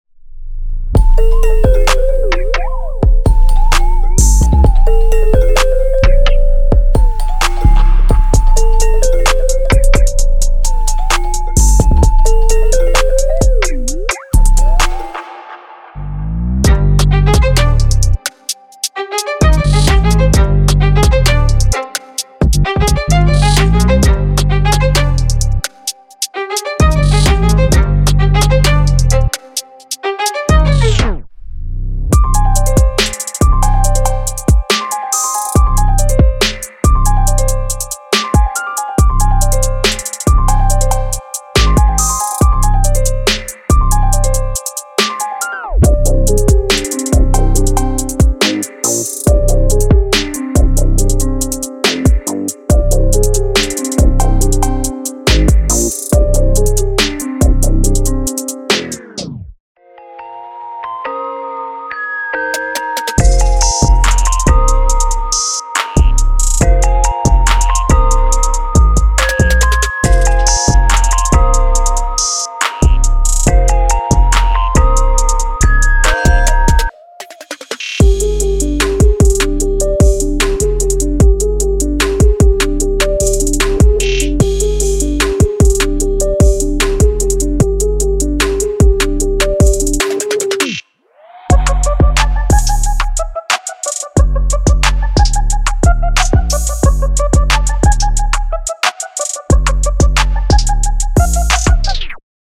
Genre:Trap
デモにはベース、ドラム、エフェクトが含まれていますが、これらはサンプルパックには含まれていません。
Tempo/Bpm 130-180